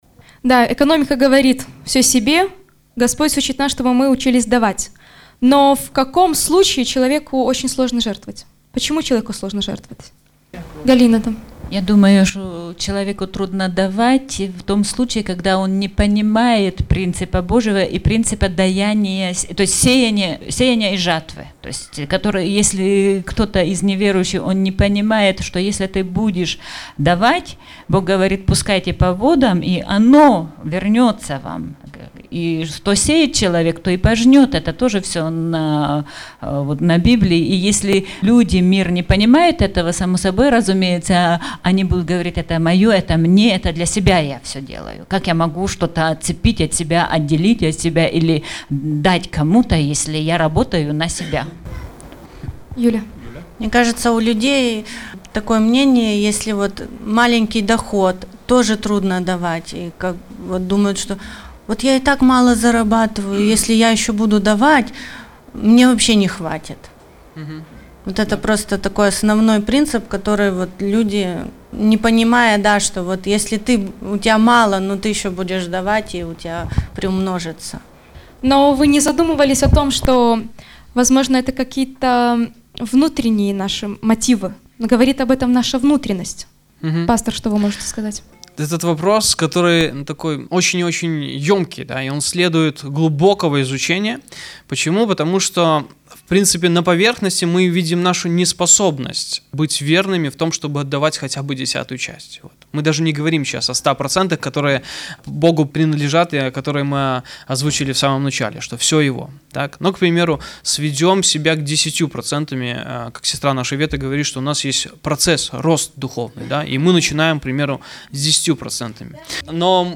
В дискуссии принимали участие все.
Мы выкладываем по мере готовности аудио-версию семинара, прошедшего в субботу 21 апреля 2012 года в стенах церкви «Святая Иоппия».